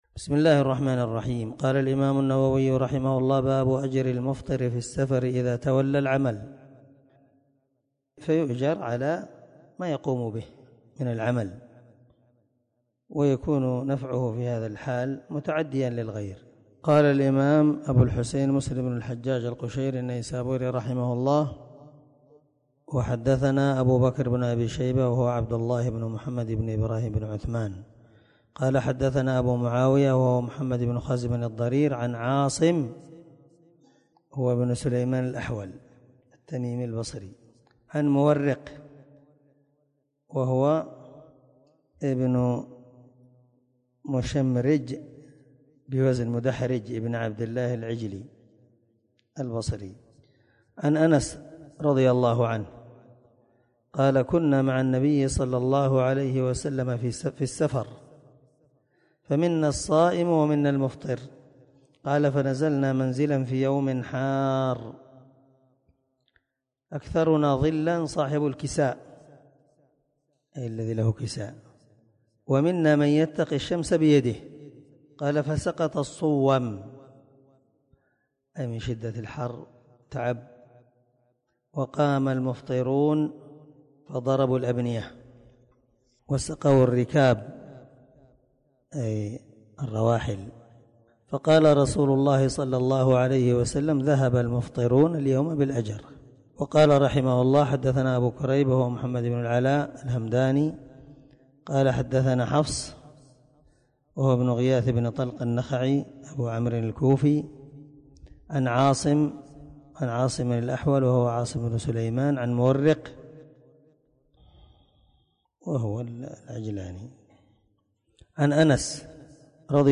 685الدرس_19من_شرح_كتاب_االصيام_حديث_رقم1119_1120_من_صحيح_مسلم